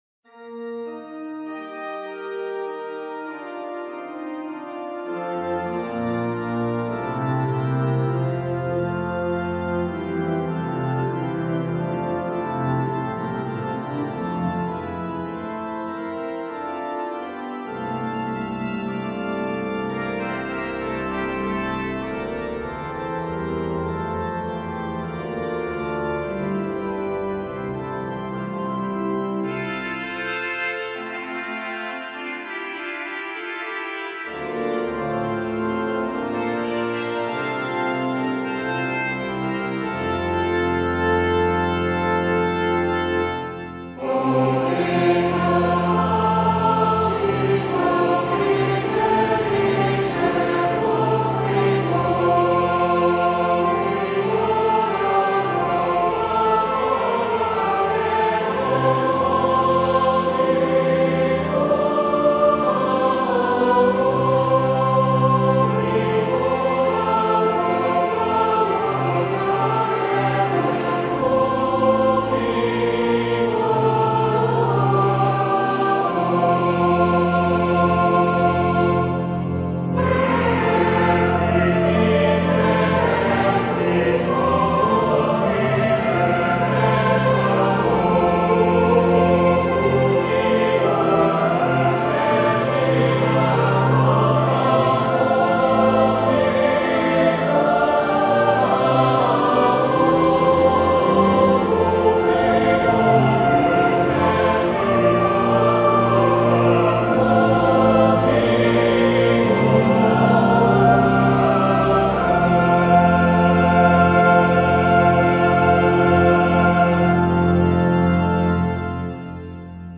Free descant and harmonization to the hymn tune CORONATION - 'All hail the power of Jesus' name' with organ prologue.
Descant to the hymn All hail the power of Jesus' name
(Audio: Prologue ad lib + hymnal verse + descant verse) [Score and audio sample updated 2023]Free score.